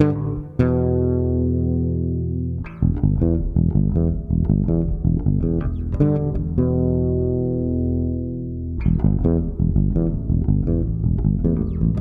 低音融合3
描述：适用于许多流派的无品类爵士贝斯的旋律
Tag: 80 bpm Fusion Loops Bass Guitar Loops 2.02 MB wav Key : E